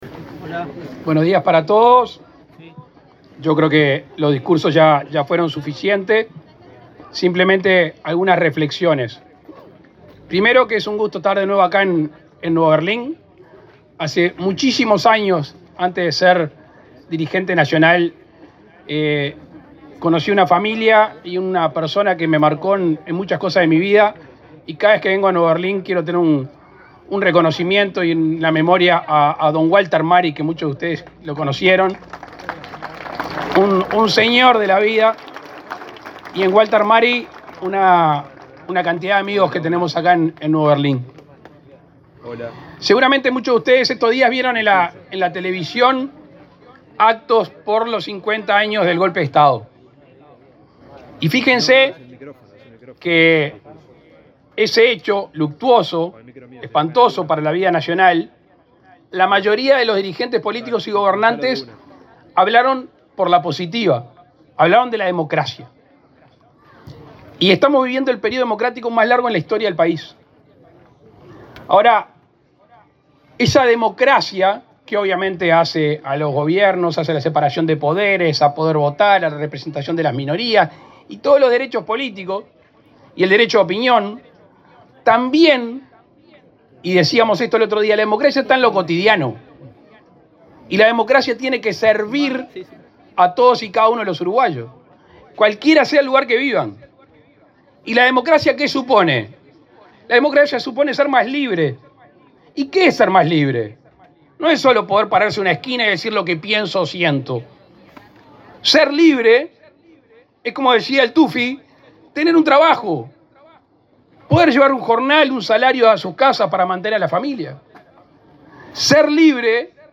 Palabras del presidente Luis Lacalle Pou
El presidente de la República, Luis Lacalle Pou, encabezó, este jueves 29, el acto de inauguración de 63 soluciones habitacionales en la localidad de